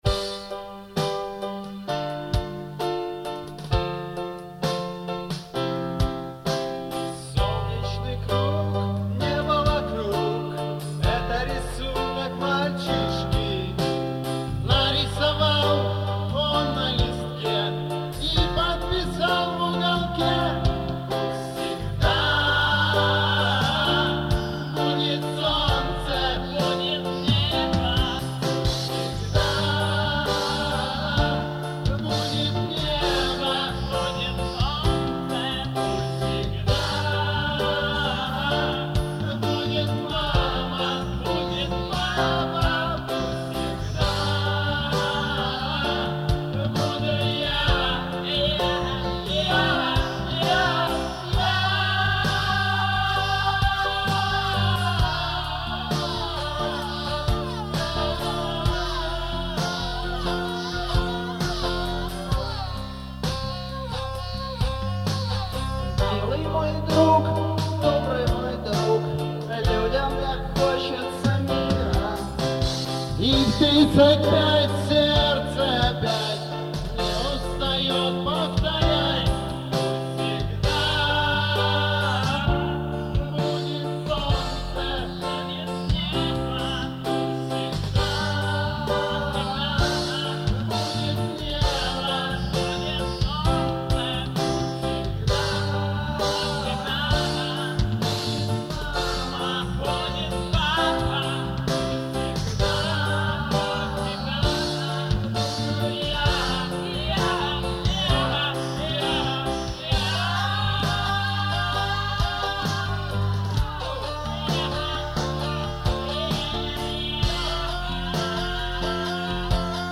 Пост панк